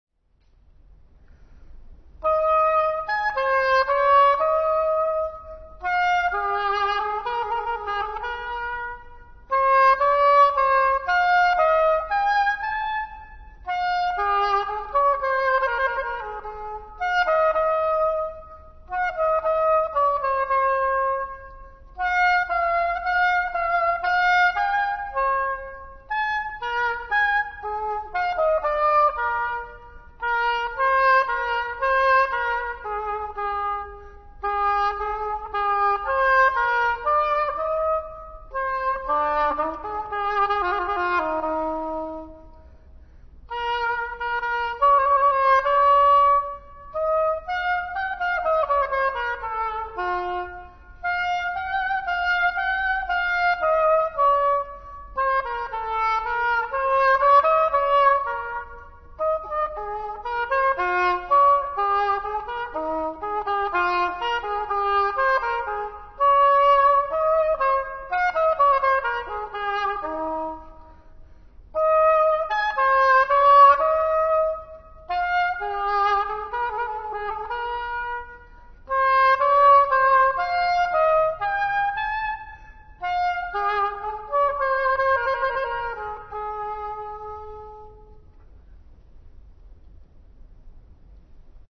Stanesby Oboe